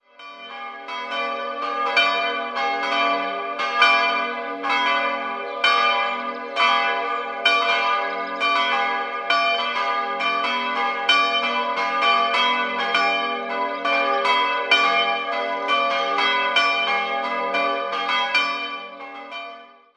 3-stimmiges Geläut: g'(+)-h'-d''' Die beiden kleineren Glocken wurden 1948 von der Gießerei Otto in Bremen-Hemelingen gegossen, die große kam 1973 hinzu.